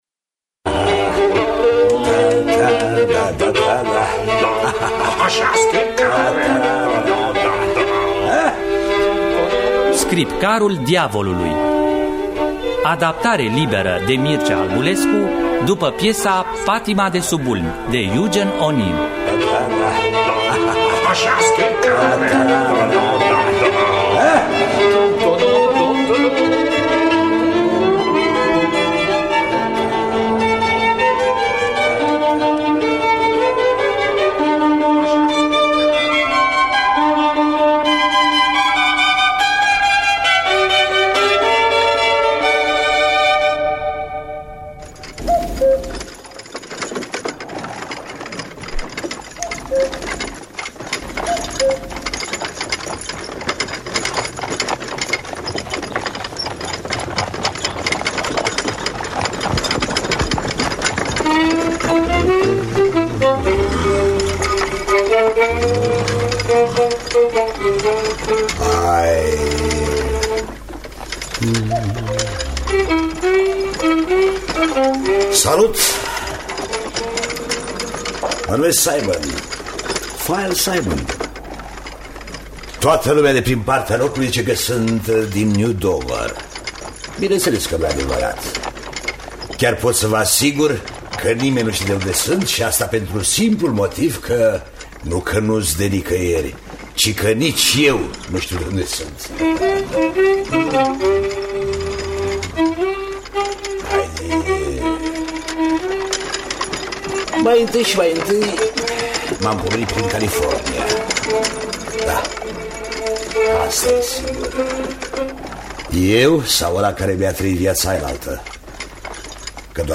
Adaptarea radiofonică de Mircea Albulescu.